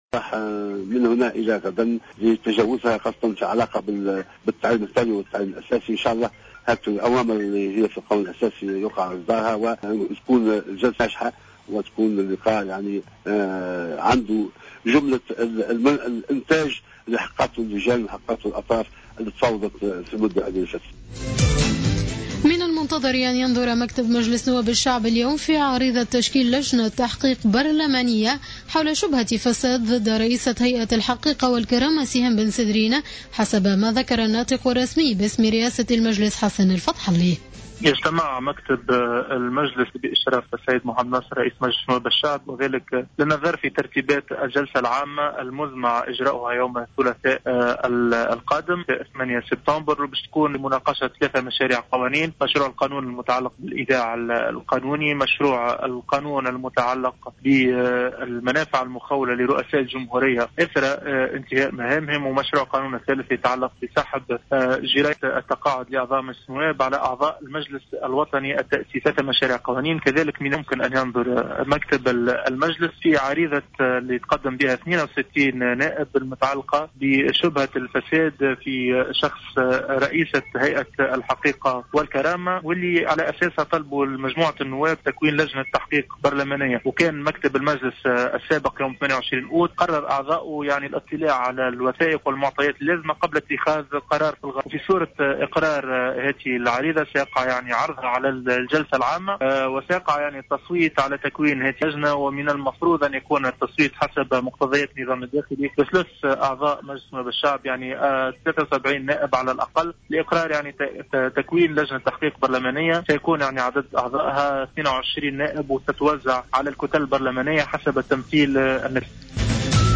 نشرة أخبار منتصف الليل ليوم الإثنين 7 سبتمبر 2015